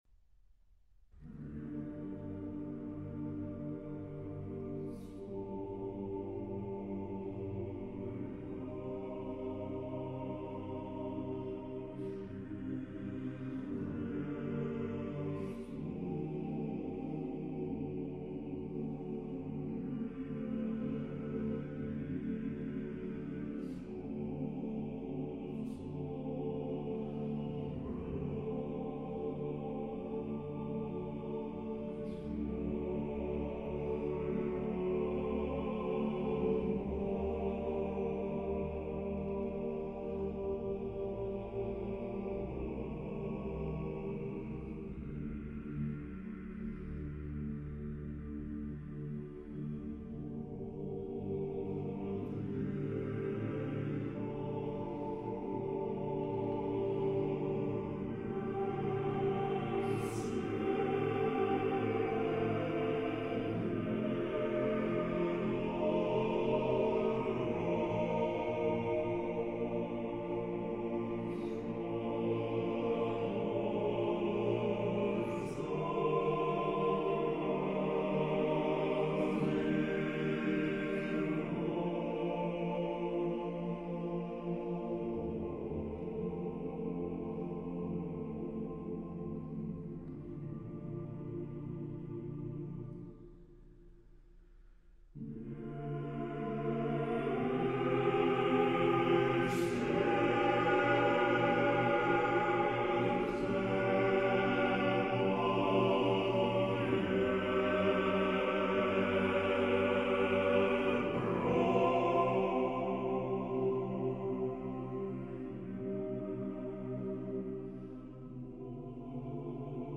А тут Профундо.То есть басы еще на октаву ниже поют.
Ортокс хор.Басы Профундо.